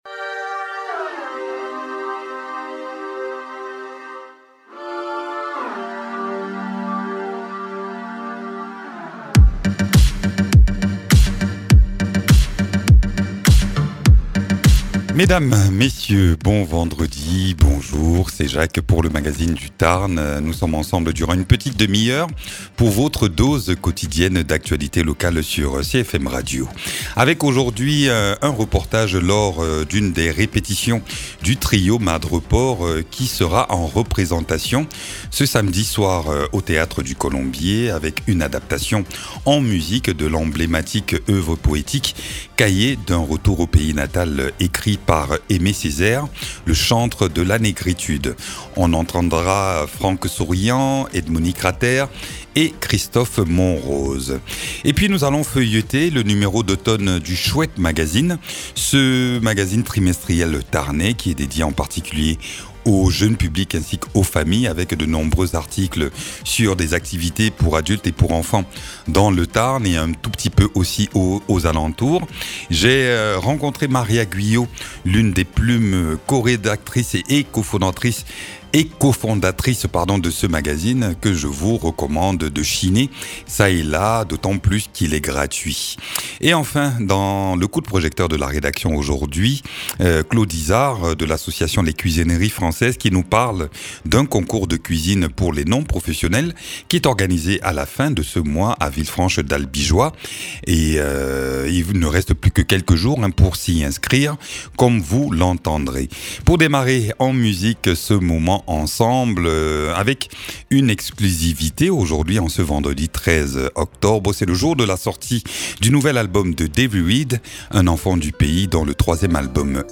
Demain au théâtre du Colombier, le cahier d’un retour au pays natal d’Aimé Césaire sera mis en musique et en scène par le trio Madrepore que nous sommes allés rencontrés lors d’une répétition. Et puis, nous feuilletons le dernier numéro du chouette magazine et dédiés aux activités dans le département et pour tous les âges.